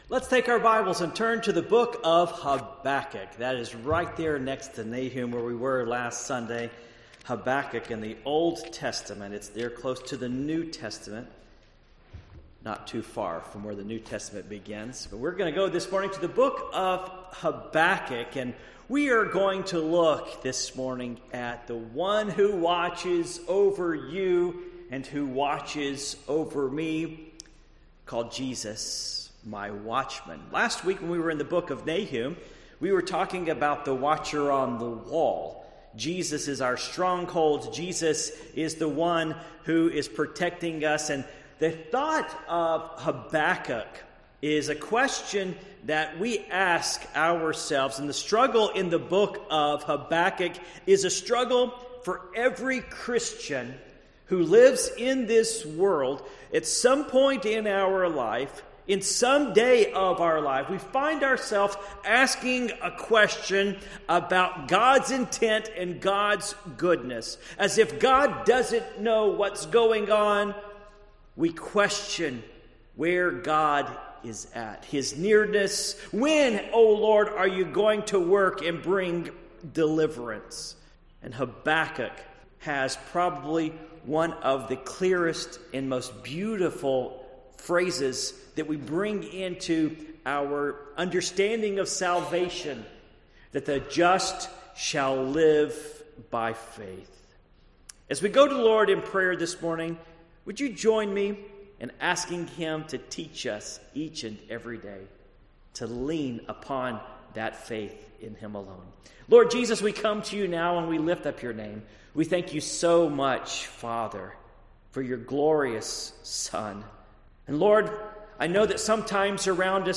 Passage: Habakkuk 2:1-4 Service Type: Morning Worship